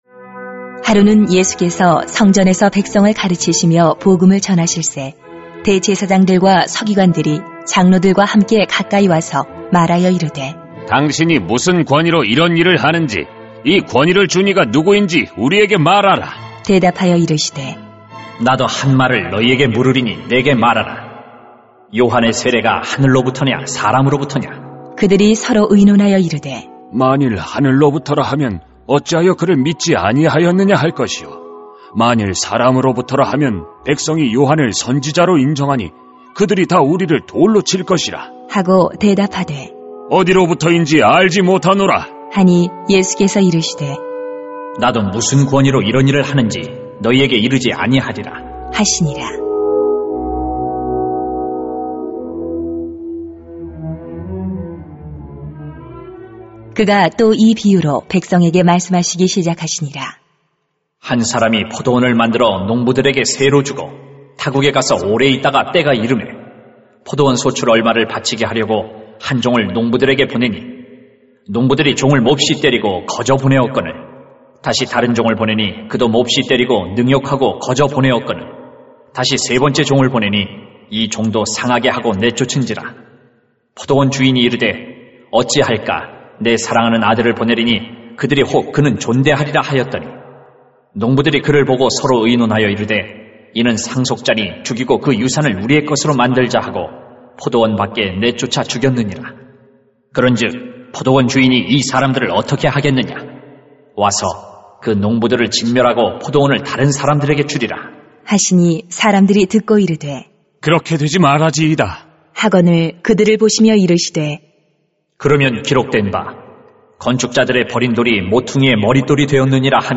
[눅 20:1-18] 예수님만이 참 권위자이십니다 > 새벽기도회 | 전주제자교회